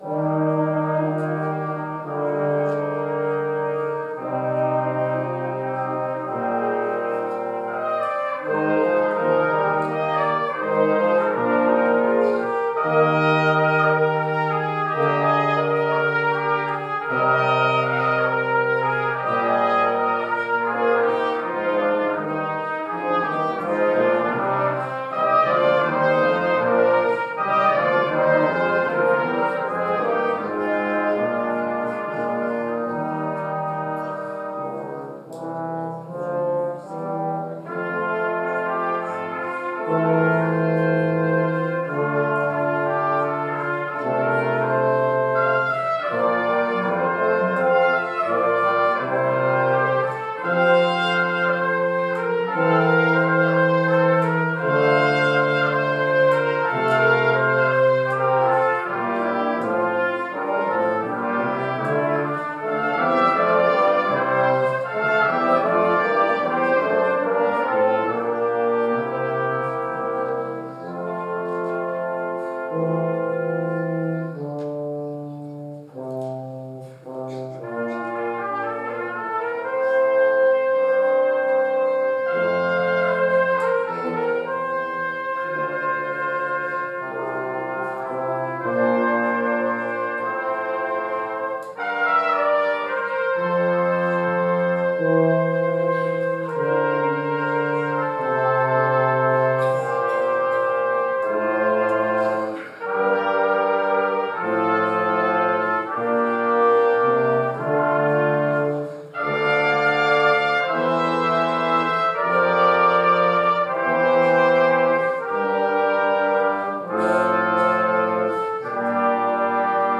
Easter Sunday Entire Service
This is the entire service for Easter, including Special Music
Celebration Choir, Kids Choirs
Brass Ensemble